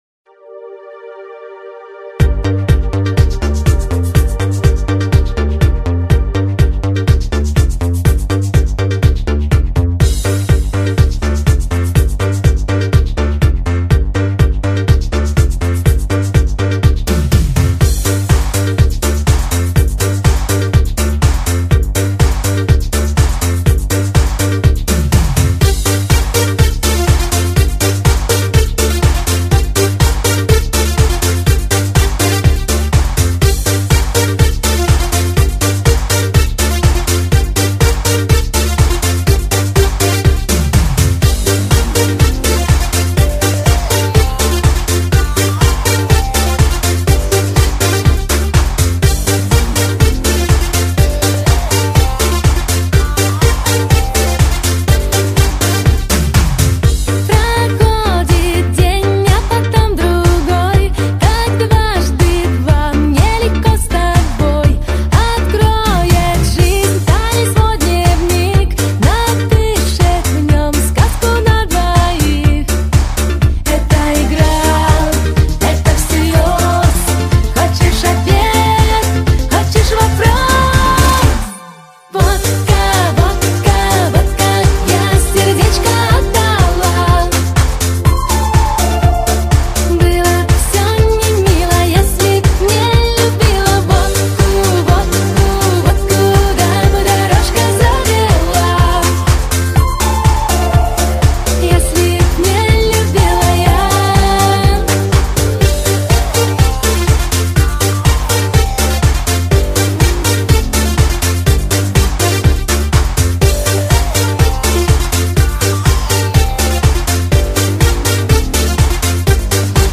【汽车音乐】
非常发烧，极品音质，环绕发烧专业试音碟。